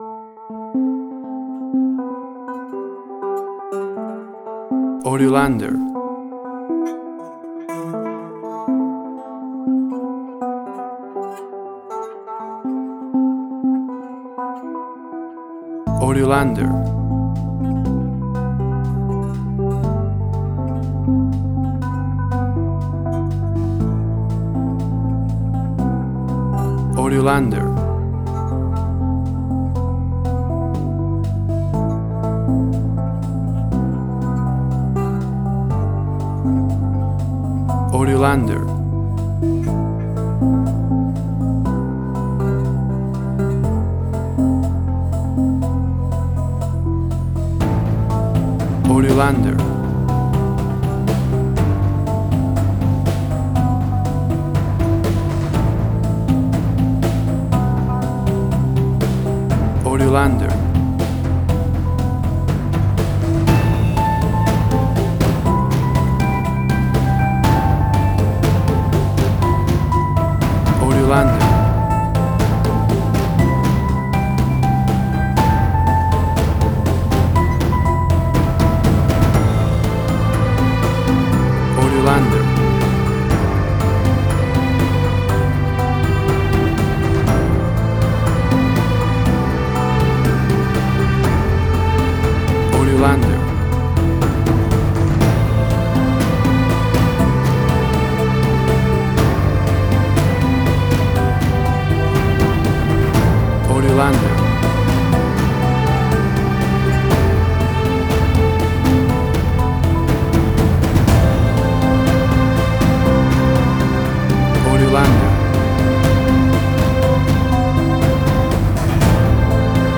Trailer Film
Tempo (BPM): 120